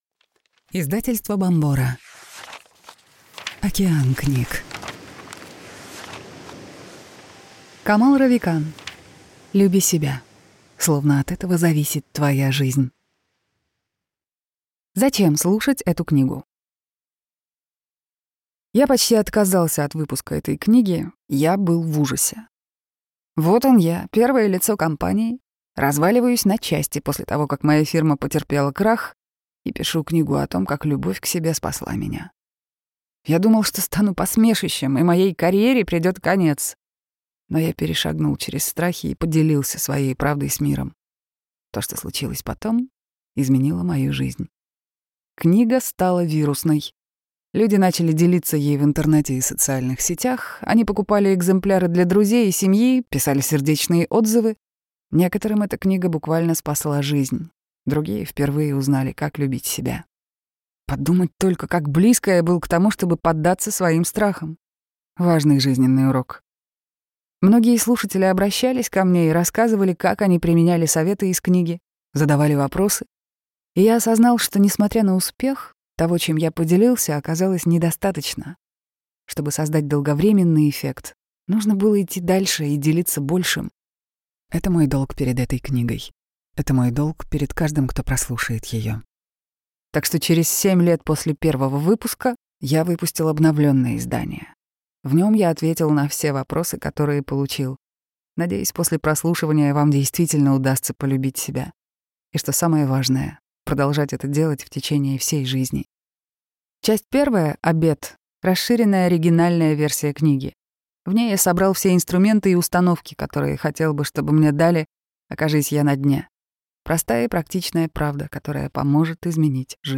Аудиокнига Люби себя. Словно от этого зависит твоя жизнь | Библиотека аудиокниг